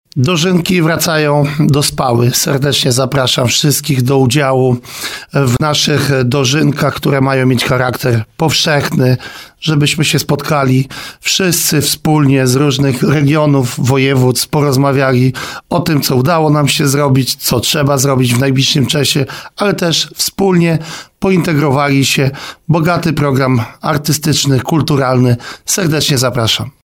Gospodarzem wydarzenia jest minister rolnictwa i rozwoju wsi Stefan Krajewski: